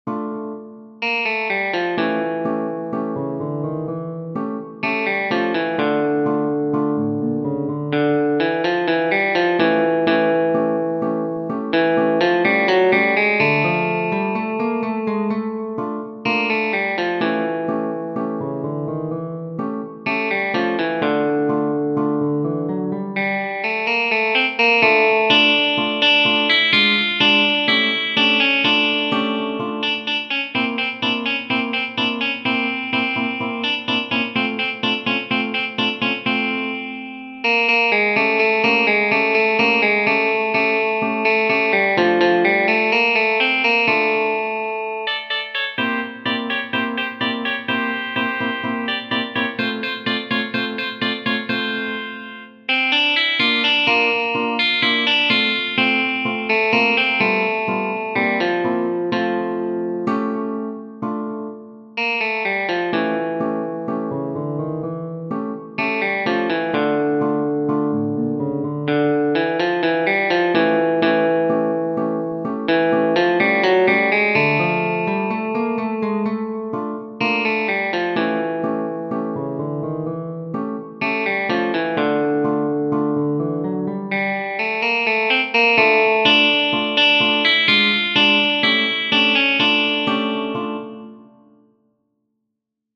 Genere: Ballabili
No_te_quiero_màs_(Tango).mp3